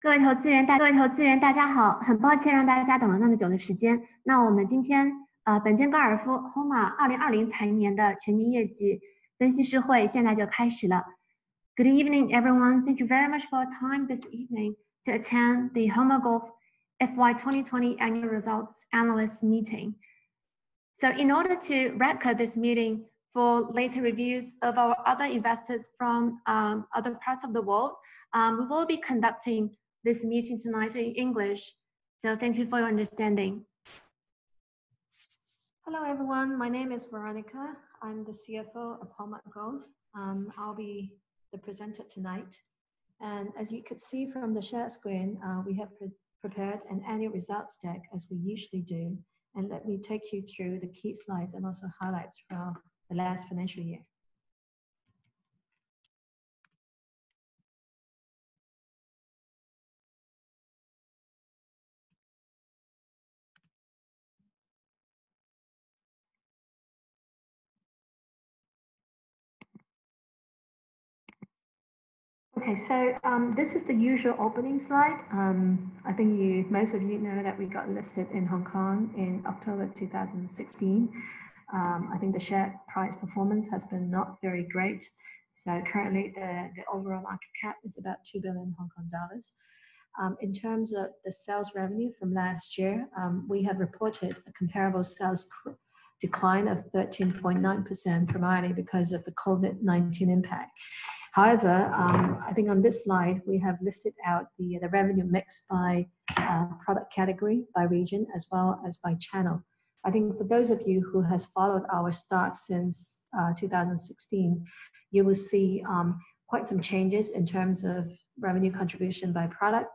6.75 MB PDF 演示材料 Result Briefing Call Replay